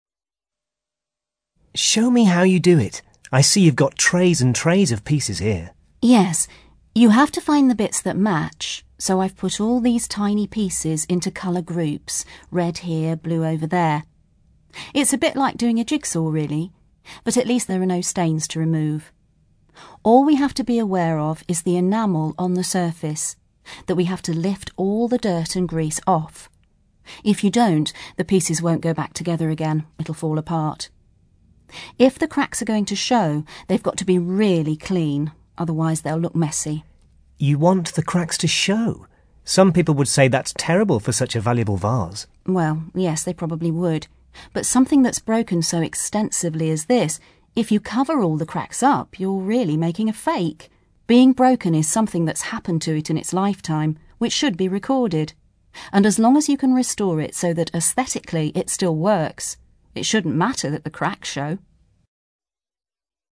You hear an interview with an expert who repairs antique vases.